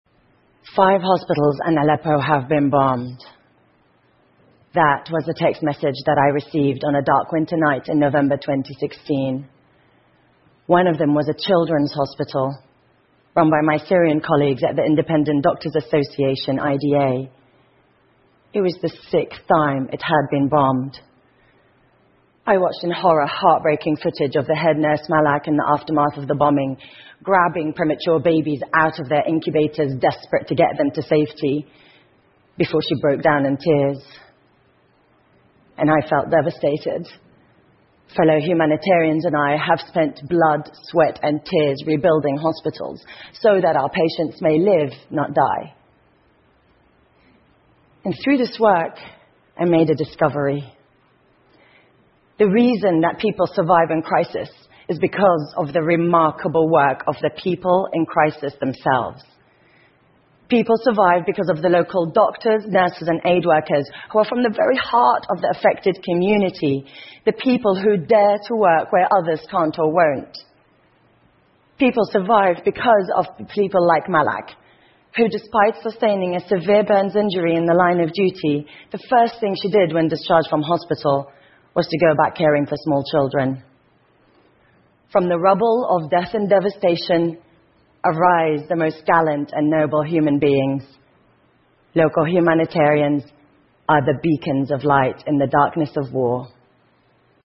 TED演讲:医生、护士和救援人员重建叙利亚() 听力文件下载—在线英语听力室